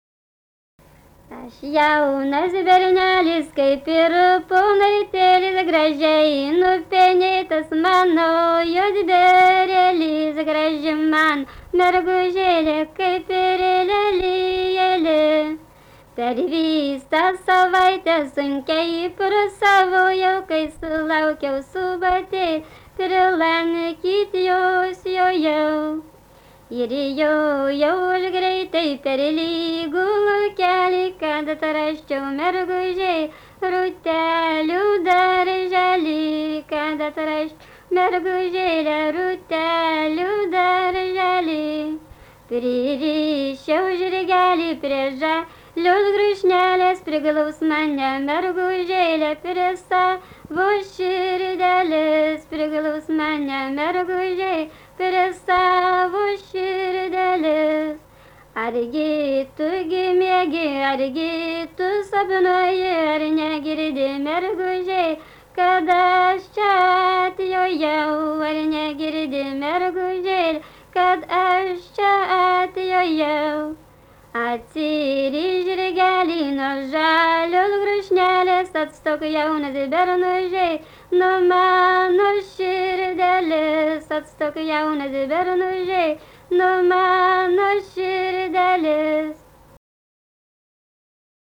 daina
Jasiuliškiai
vokalinis